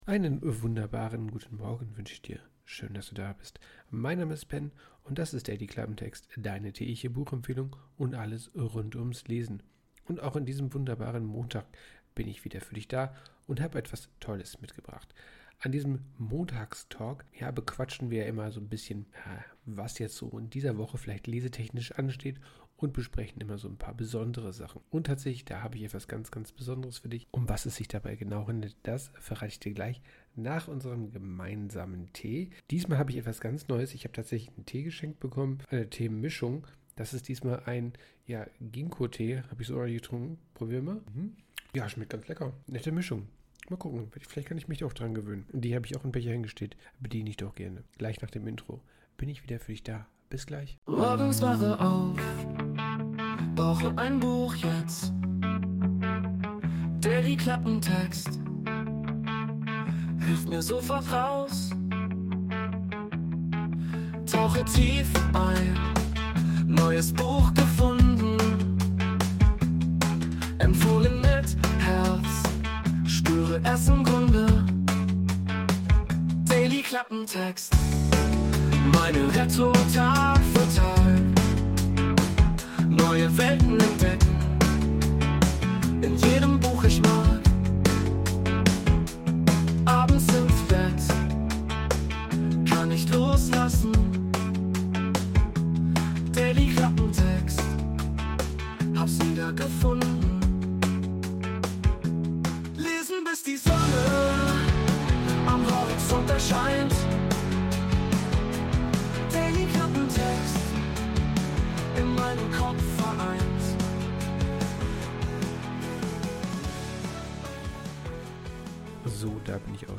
Guten Morgen! das Wochenende ist vorbei und ich hatte die Gelegenheit, an der "Nach der Bibliothek"-Veranstaltung teilzunehmen, und zwar an einer Reading Party in Hamburg: in den Bücherhallen. Dazu gibt es ein kurzes Interview mit zwei Damen der Hamburger Bücherhallen, die maßgeblich an der...